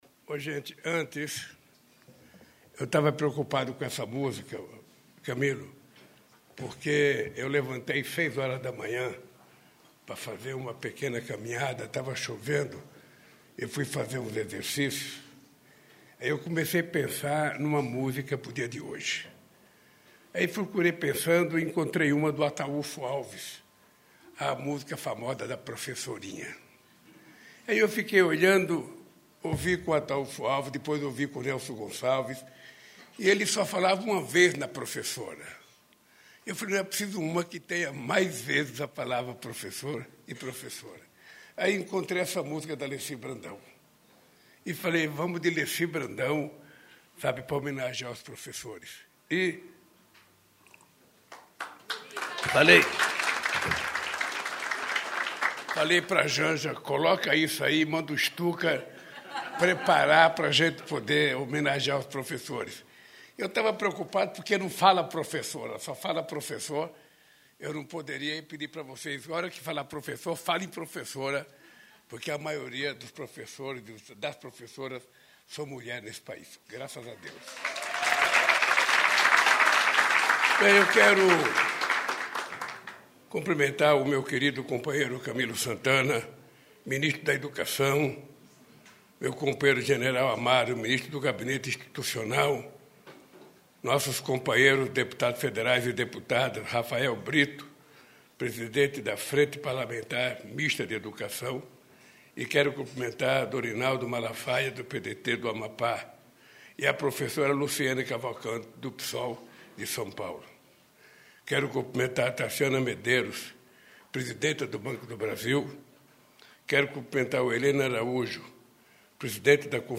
Íntegra do discurso do presidente Luiz Inácio Lula da Silva na cerimônia de início da retomada das operações da Fábrica de Fertilizantes Araucária Nitrogenados S.A. (ANSA) e anúncio de investimentos na Refinaria do Paraná (Repar), nesta quinta-feira (15), em Araucária (PR).